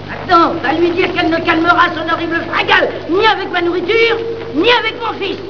les invectives de la maman